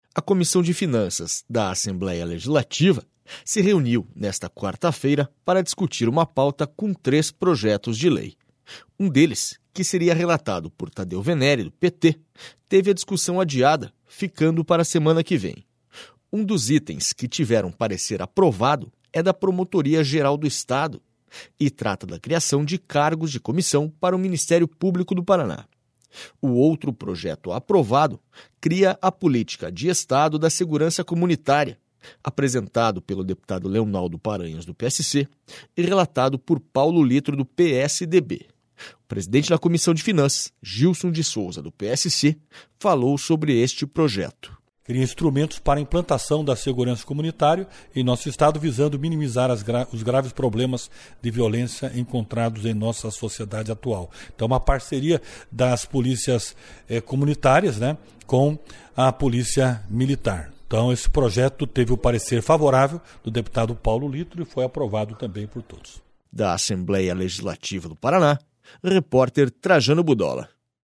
SONORA GILSON DE SOUZA